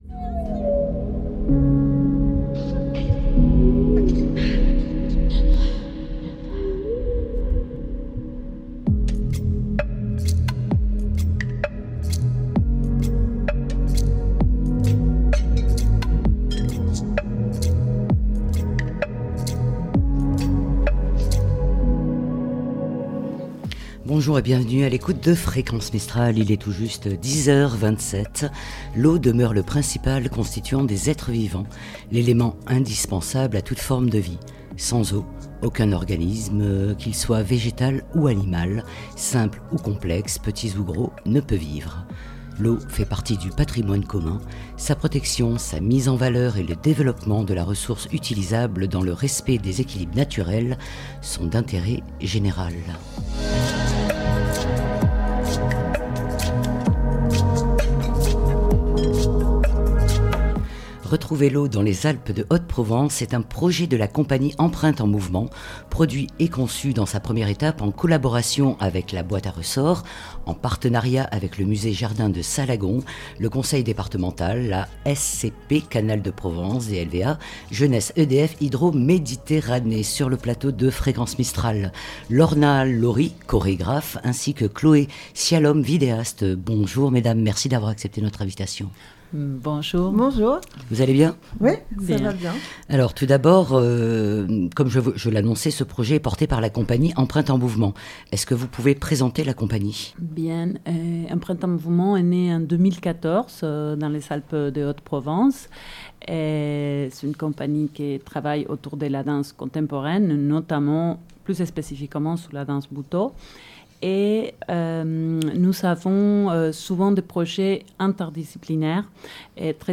Au micro de Fréquence mistral :